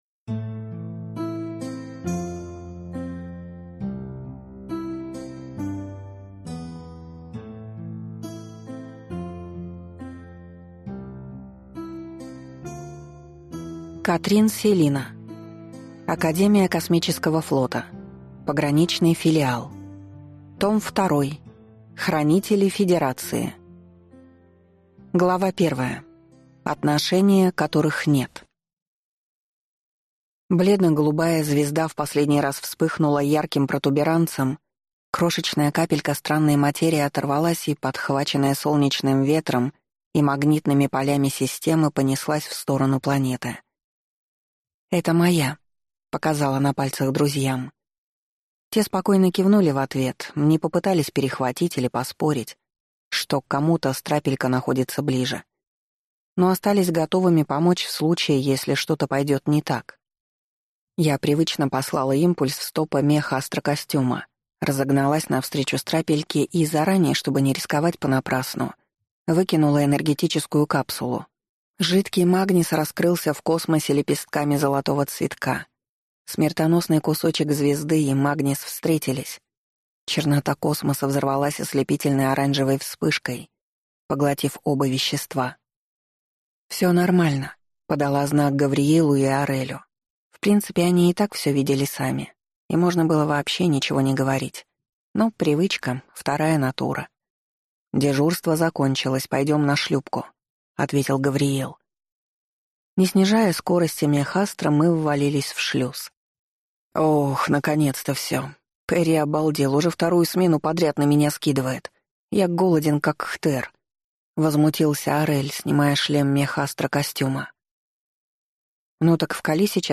Аудиокнига Академия Космического Флота. Пограничный филиал. Том 2. Хранители федерации | Библиотека аудиокниг